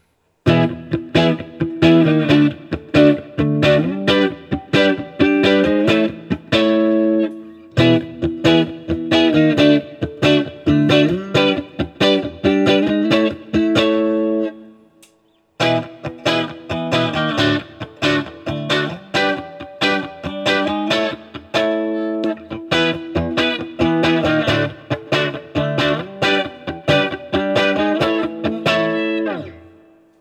All recordings in this section were recorded with an Olympus LS-10.
For each recording, I cycle through all four of the possible pickup combinations, those being (in order): neck pickup, both pickups (in phase), both pickups (out of phase), bridge pickup.
Chords